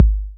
808-Kicks23.wav